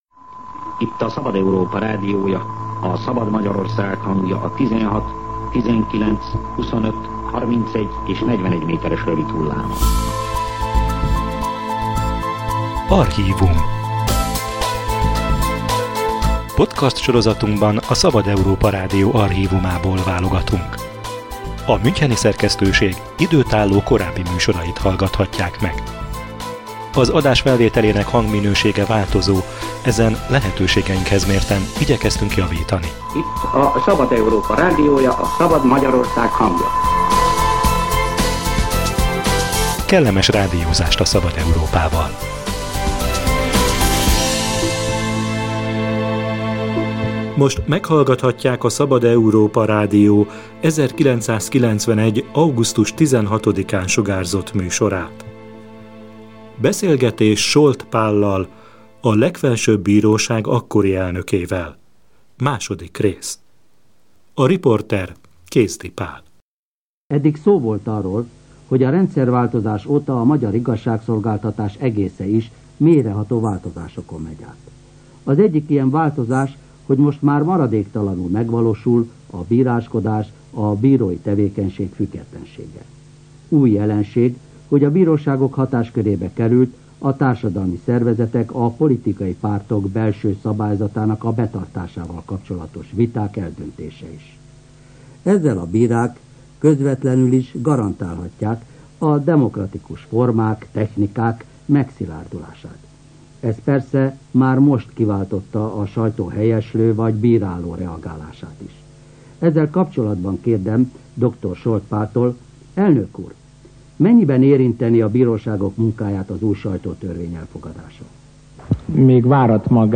Harminc éve dolgozott bíróságokon és egy éve állt a Legfelsőbb Bíróság élén a jogász, amikor a Szabad Európa Rádió interjút kért tőle. A beszélgetés első részében szóba került az újonnan felállított Alkotmánybíróság szerepe, az úgynevezett papi perek lezárása és a bírák függetlenségének igénye.